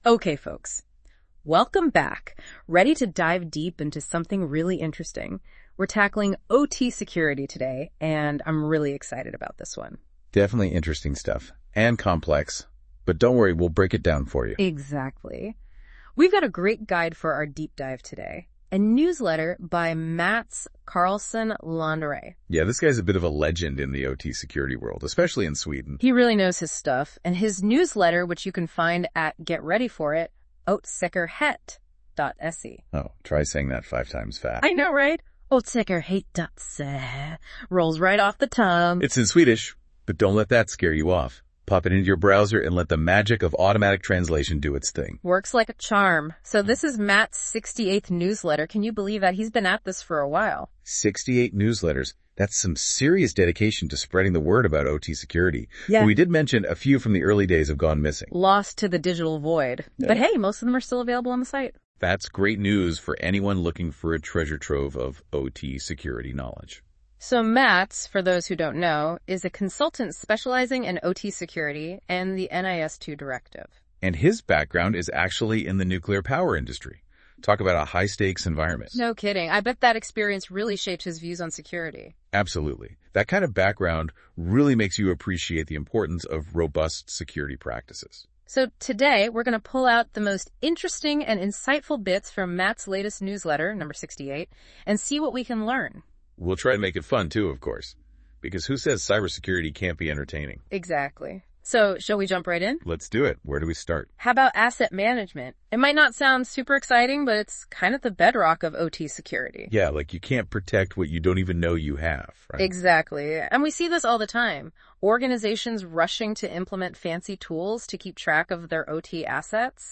Nyhetsbrevet diskuteras i en ny "podd"
Nu kan du höra mina goda AI-vänner Aileen och Aiden diskutera det jag skrivit i deras podd.